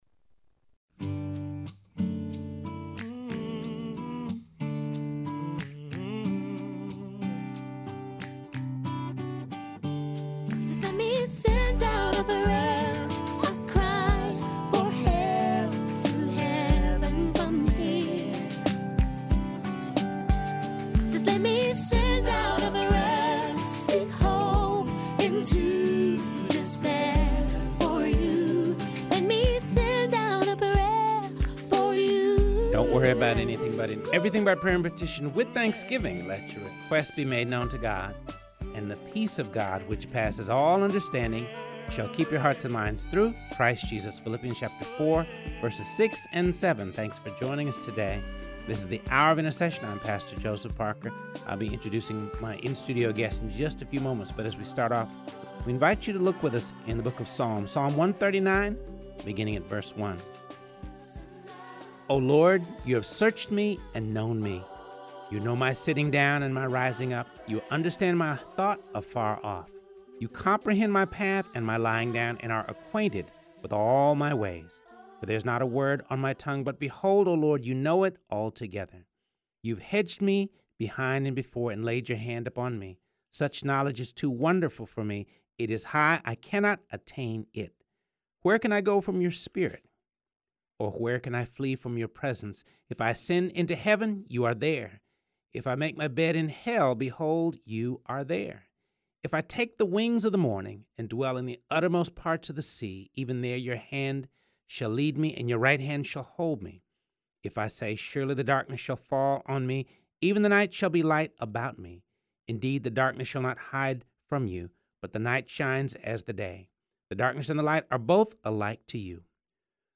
in studio as they discuss the power of God’s word in our everyday lives.